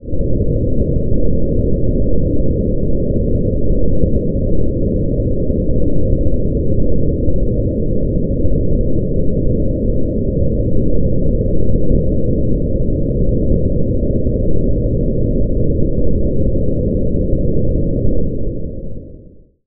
underwater-sound